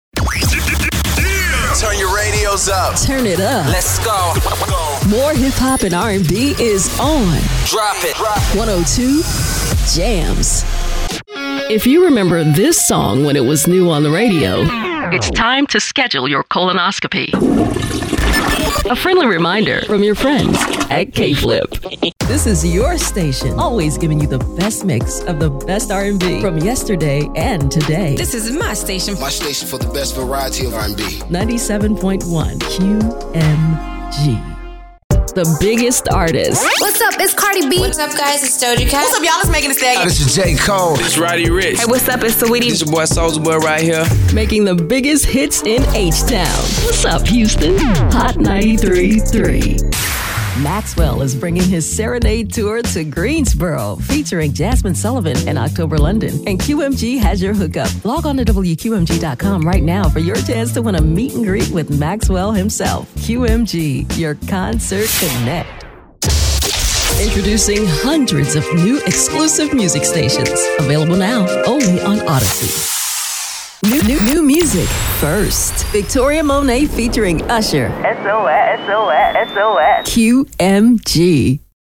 Commercial Demo
American English